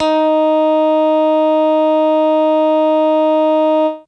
55bc-bse16-d#4.aif